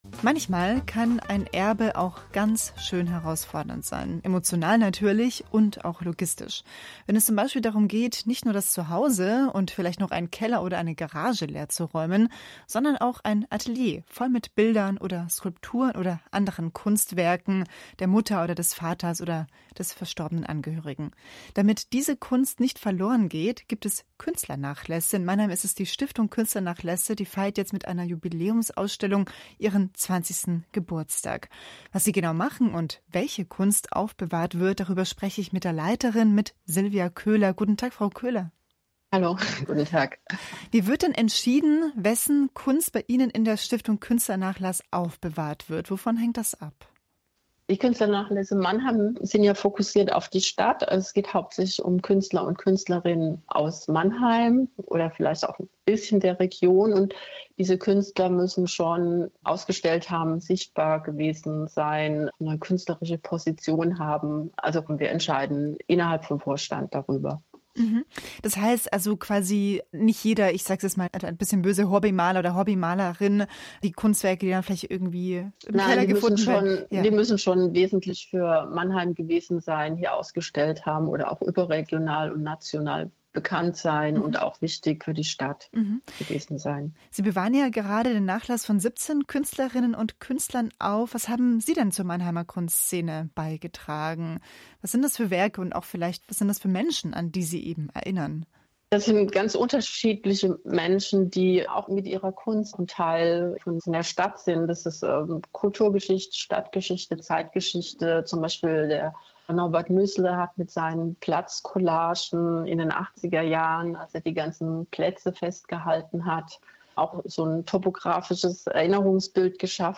Beitrag Kultur am Mittag Interview